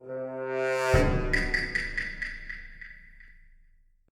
Among Us Role Reveal Sound Effect